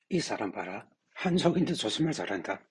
korean-spoken-by-chinese.mp3